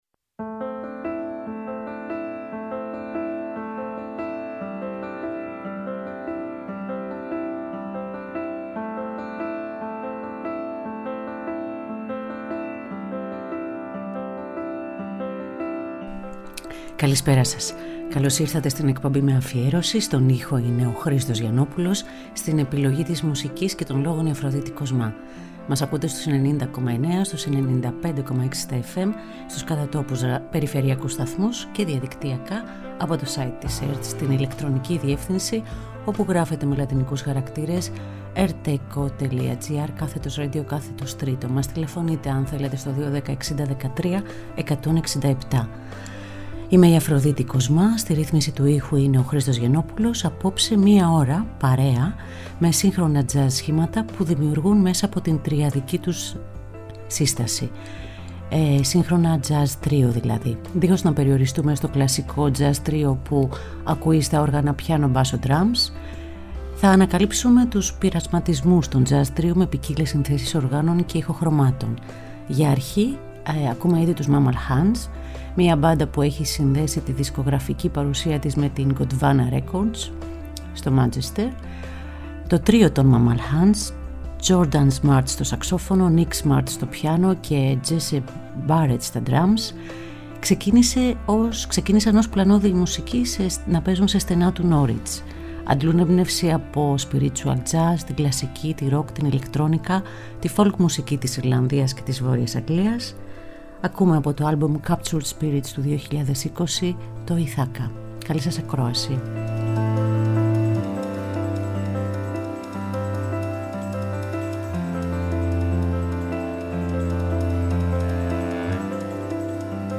Ζωντανά από το στούντιο